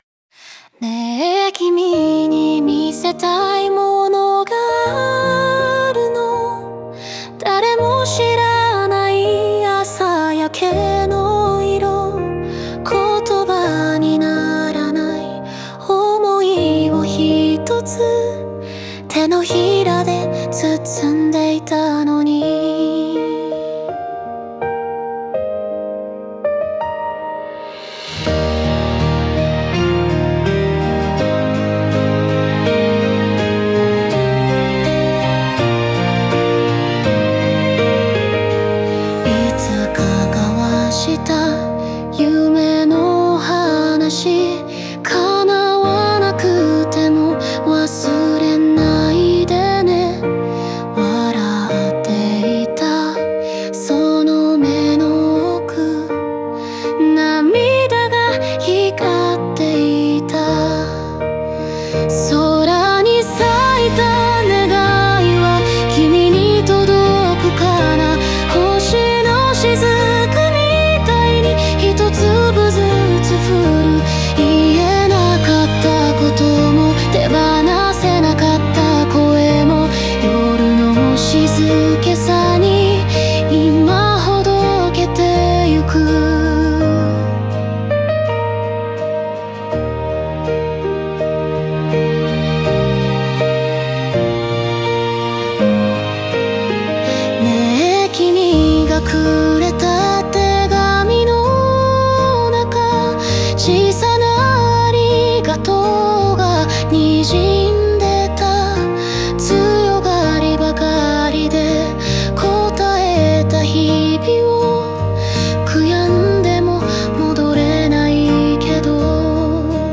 大切な人との別れと再生を描いたバラード。